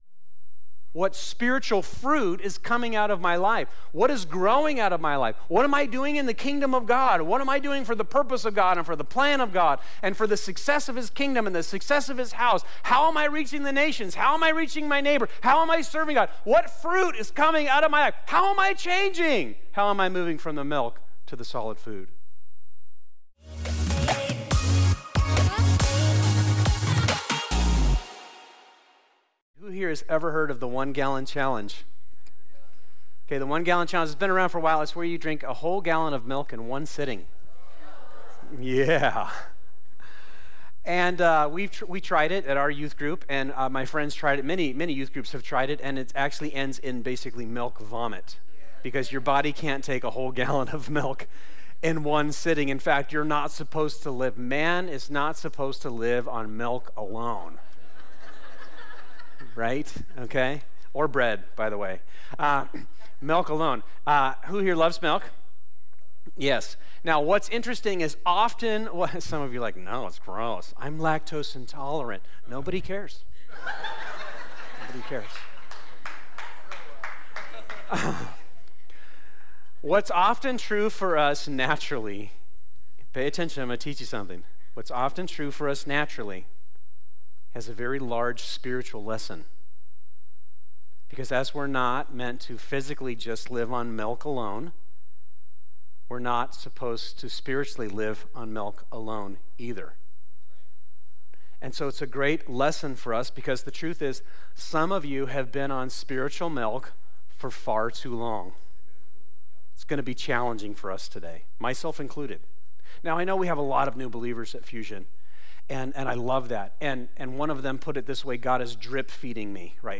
This is part 4 of “Stay the Course,” our sermon series at Fusion Christian Church on the book of Hebrews.